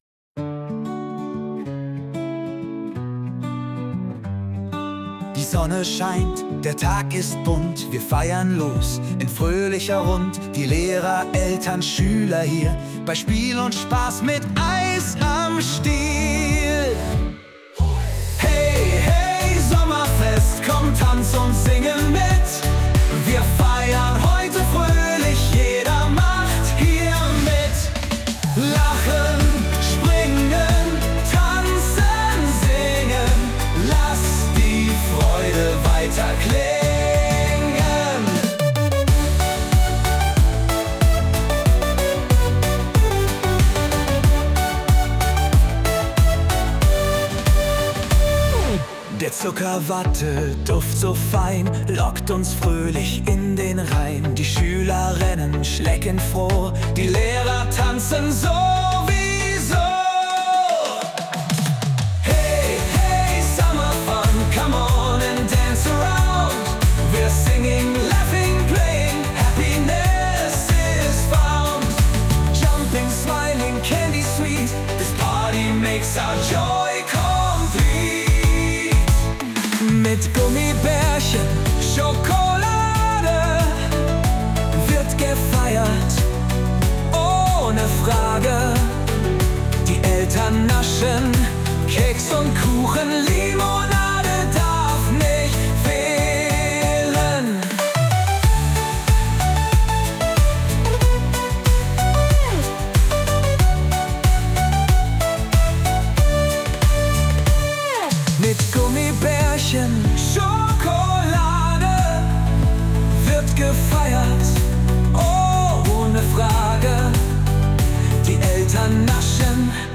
Die KI liefert zu eigenen oder ebenfalls mit KI generierten Texten komplette Lieder mit realistischen Gesangsstimmen, Melodien und Instrumenten, die dann individuell angepasst werden können.